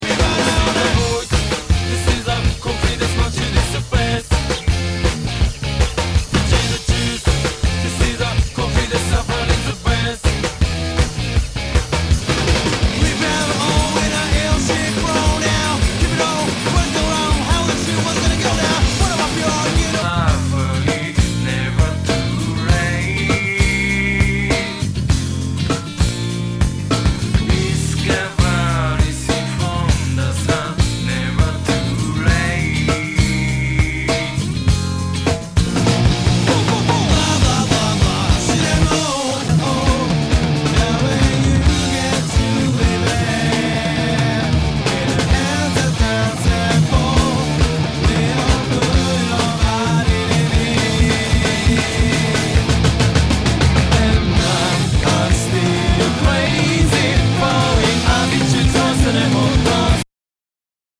和モノ/JAPANEASE GROOVE